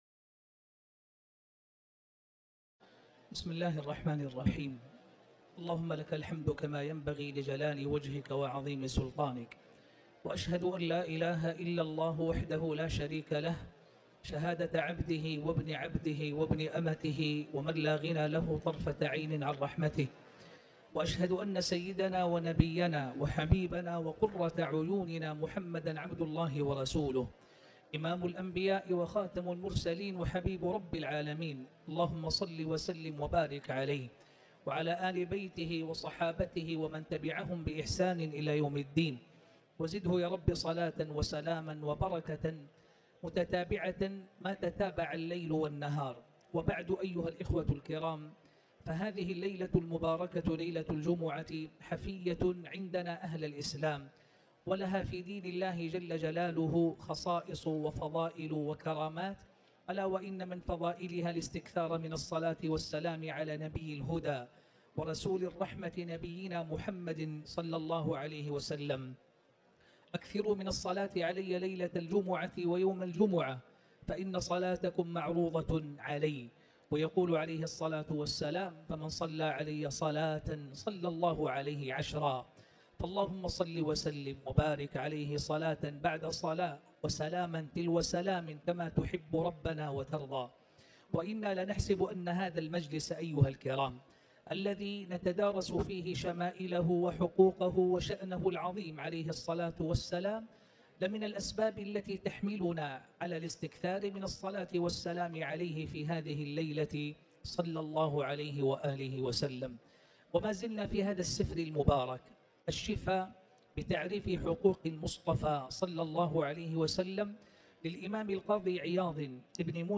تاريخ النشر ١٨ جمادى الأولى ١٤٤٠ هـ المكان: المسجد الحرام الشيخ